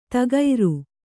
♪ tagairu